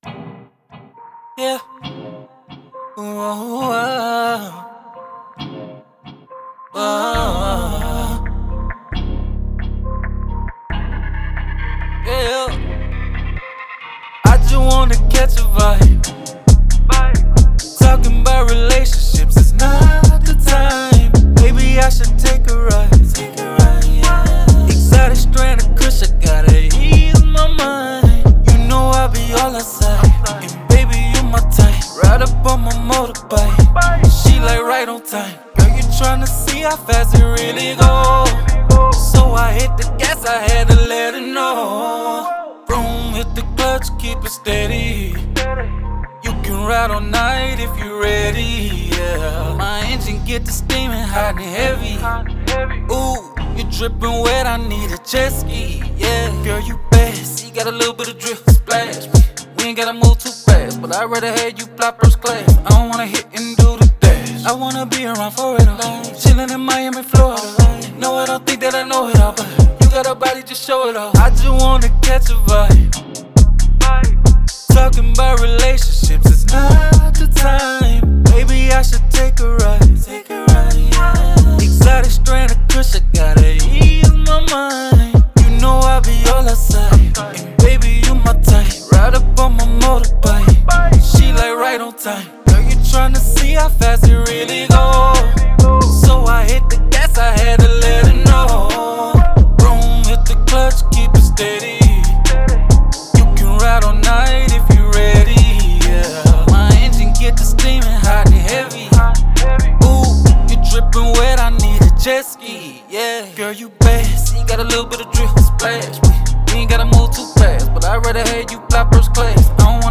R&B, Hip Hop
C Minor